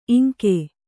♪ iŋke